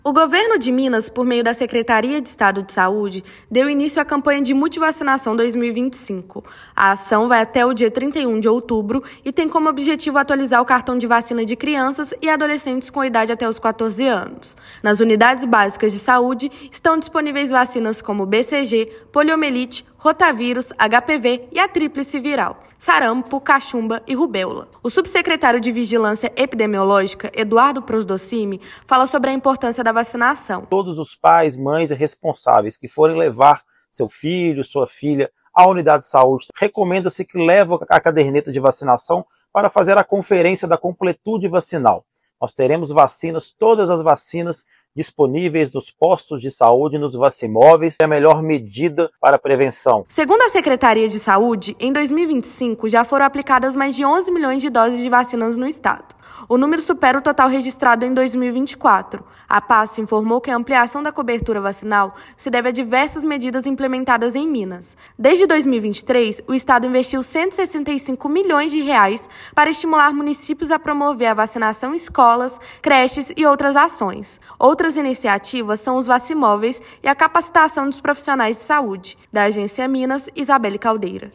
Estado reforça a importância de manter o cartão de vacinação em dia. Ouça matéria de rádio.